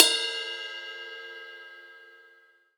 Percs
DJP_PERC_ (19).wav